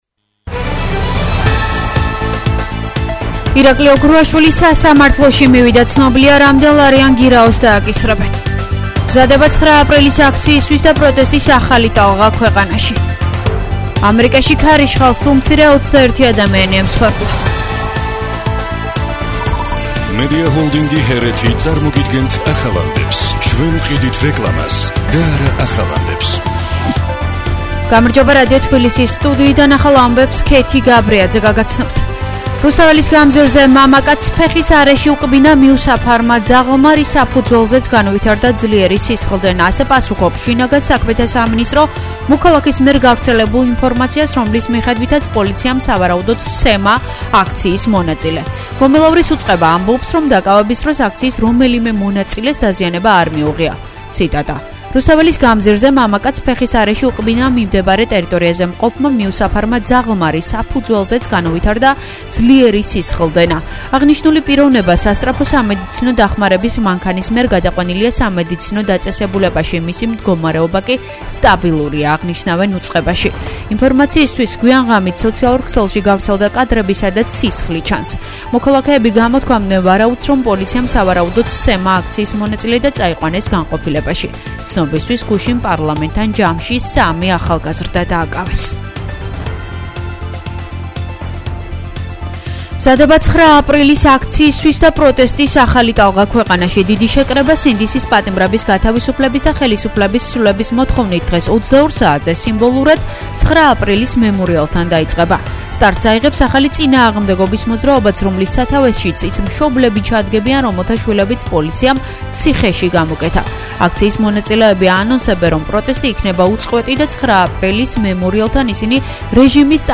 ახალი ამბები 11:00 საათზე